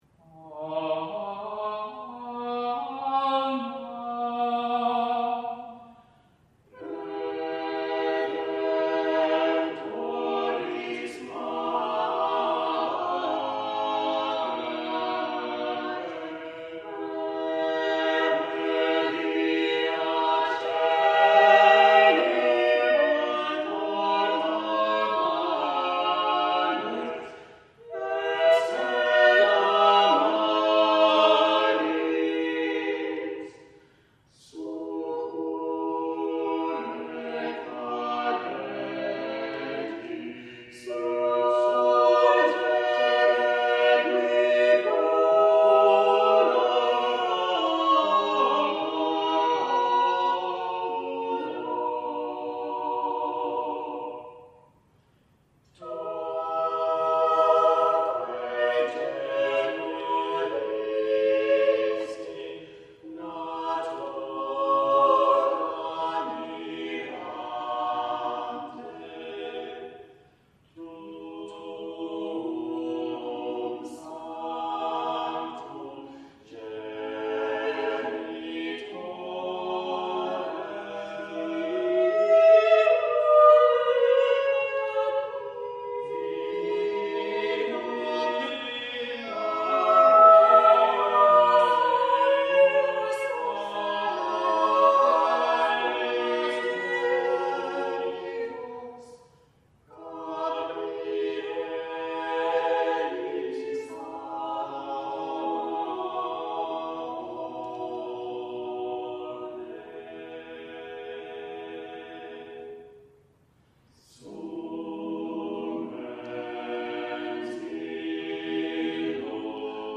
Archdiocese of Brisbane A hymn to Mary